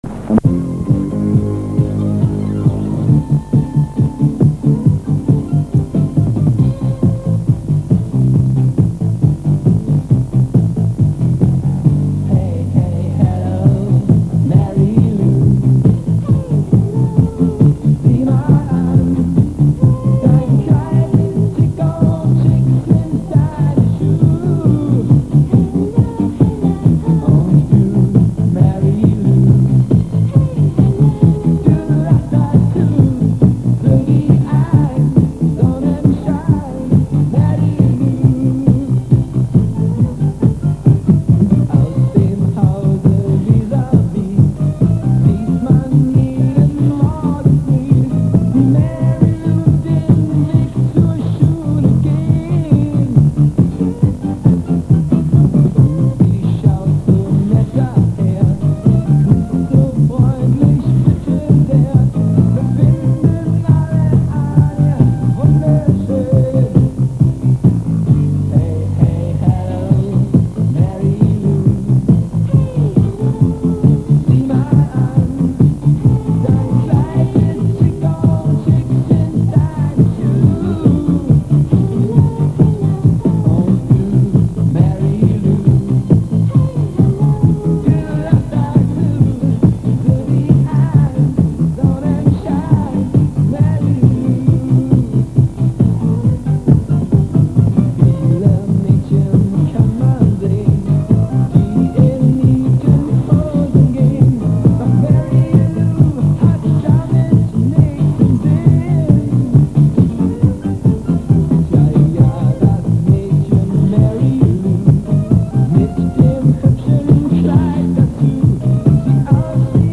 Прошу прощения за низкое качество звучания.